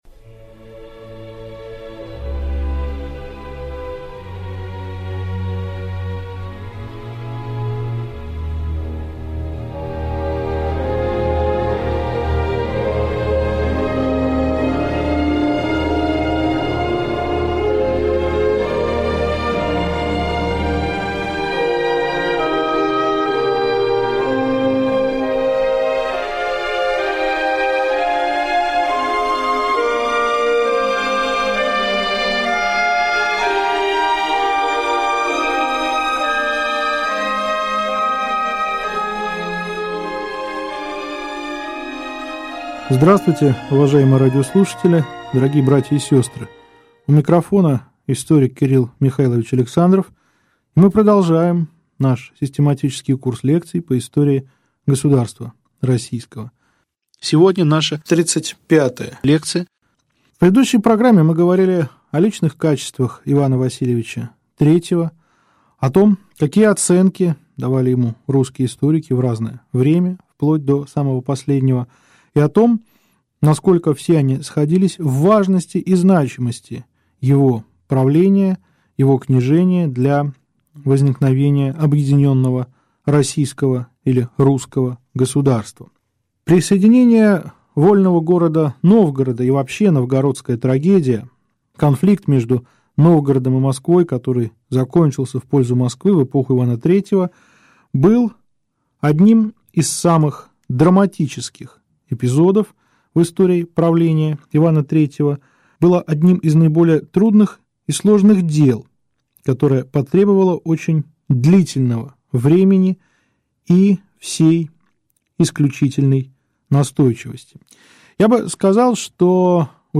Аудиокнига Лекция 35. Московско-Новгородские отношения при Иване III | Библиотека аудиокниг